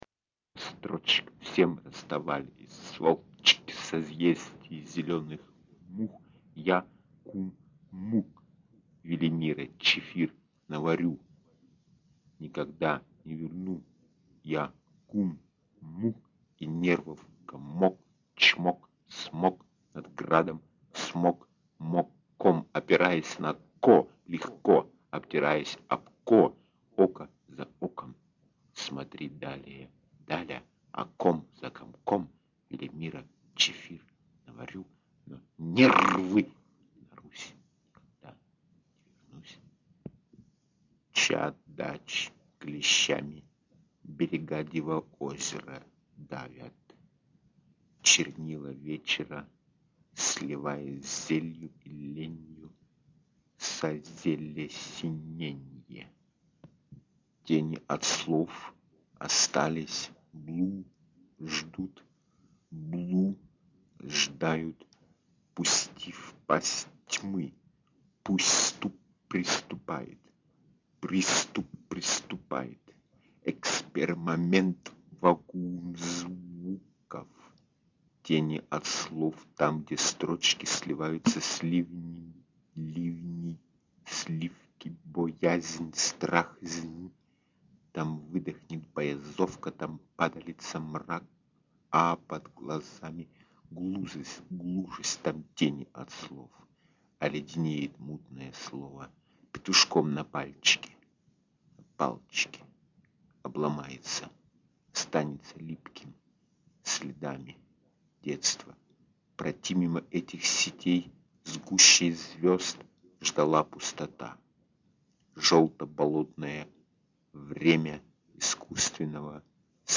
Unter anderem auch Sprechkasetten, die nur zum persönlichen Gebrauch als Kontroll- und Übematerial gedacht waren, aber durch ihre suggestive ruhige Kraft zum meditativen Mitvollzug einladen.
Auschnitt aus: Schwarze Übungskasette: